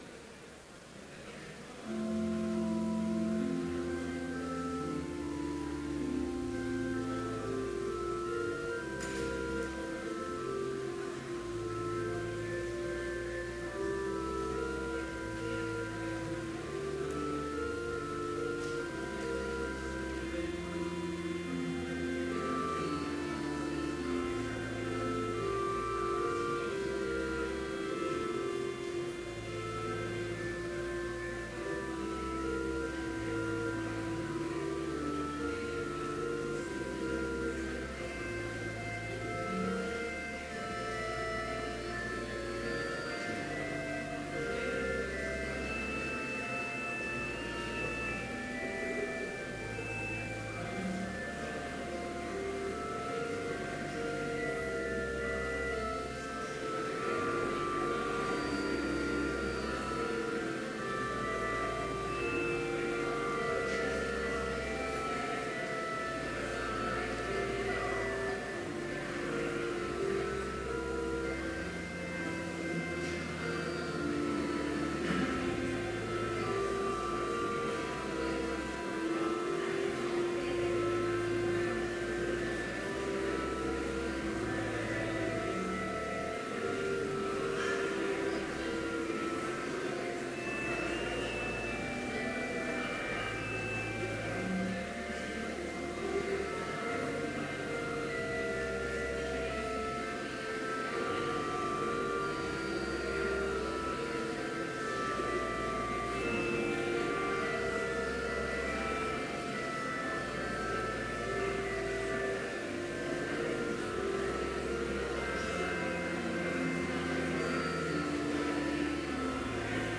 Complete service audio for Chapel - April 4, 2018